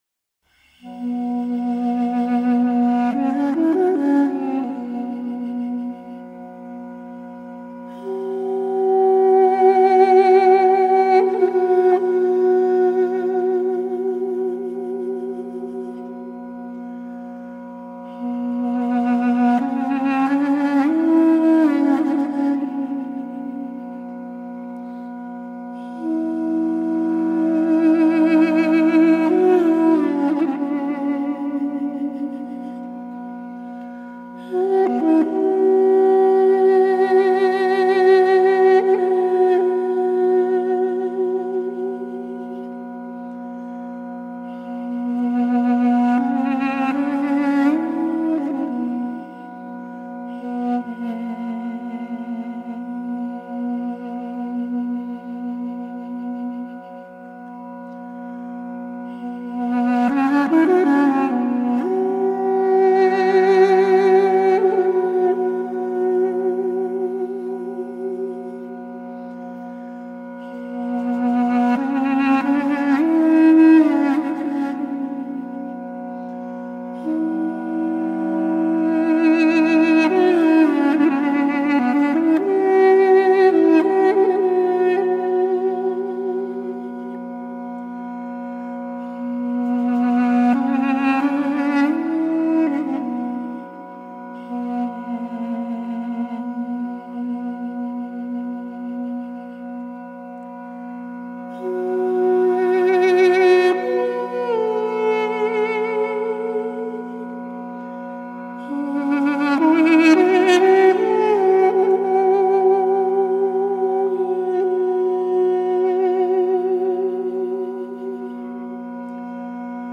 Traditional Armenian Instrument
Duduk
Audio file of the Duduk
Armenia-flute.mp3